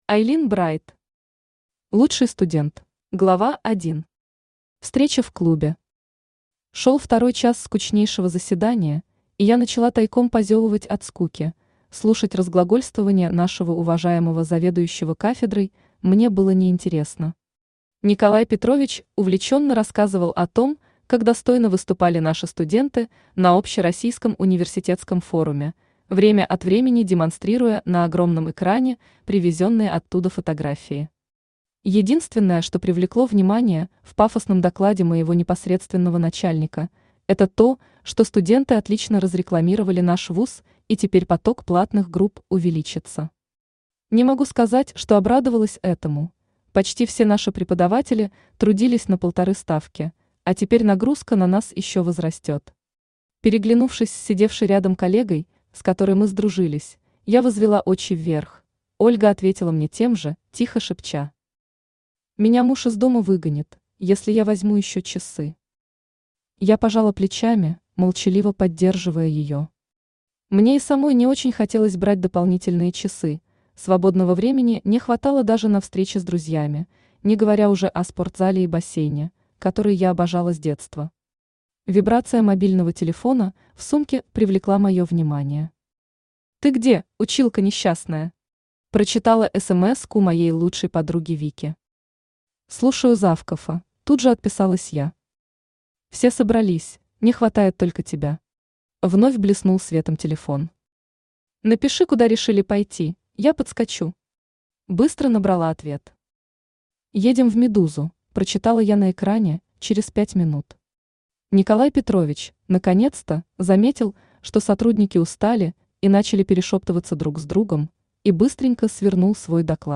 Aудиокнига Лучший студент Автор Айлин Брайт Читает аудиокнигу Авточтец ЛитРес.